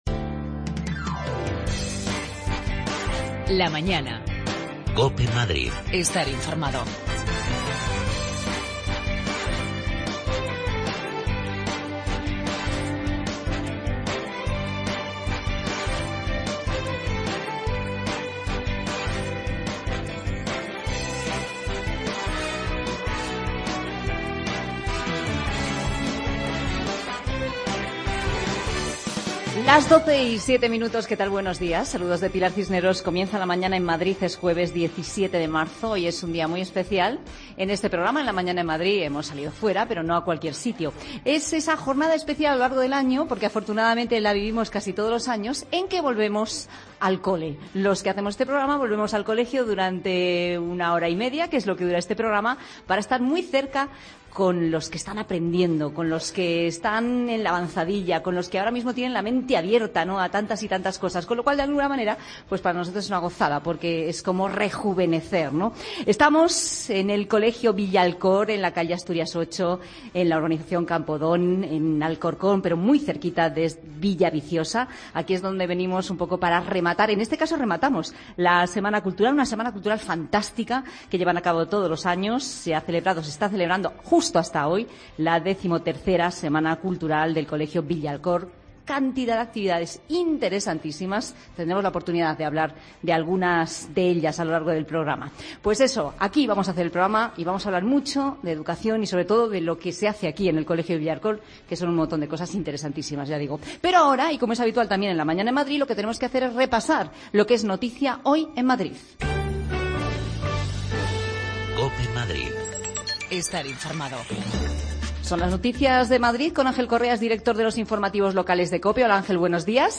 AUDIO: Hoy hacemos el programa en directo desde el Colegio Villalkor, el centro se encuentra en su Semana Cultural.